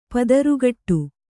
♪ padarugaṭṭu